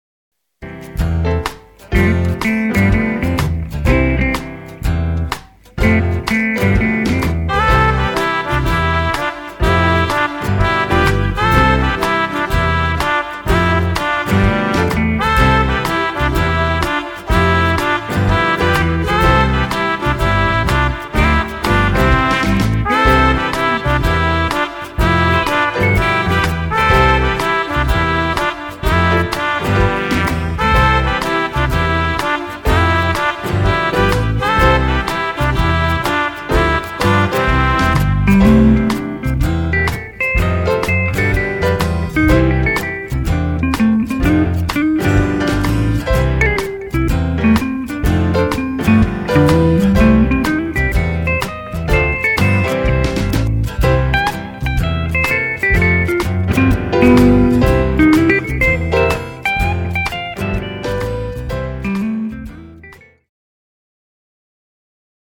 Cued Sample
Two Step